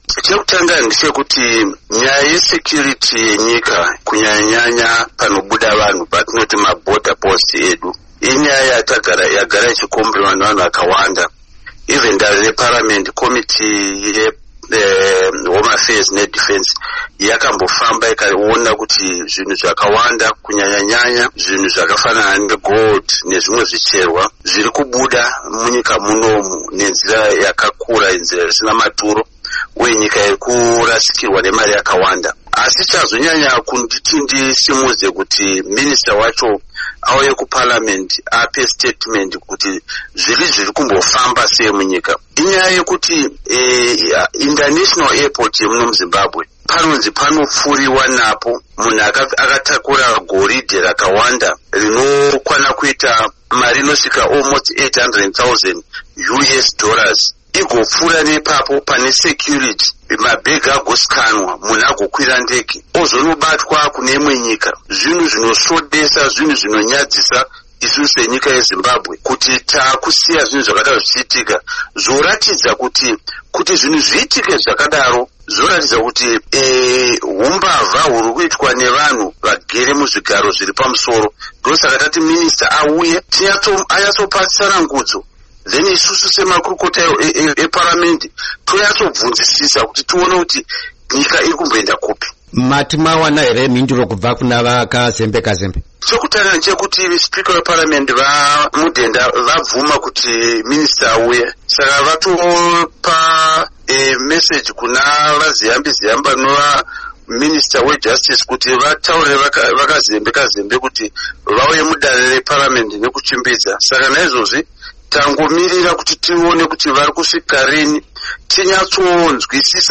Hurukuro naVaEdwin Mushoriwa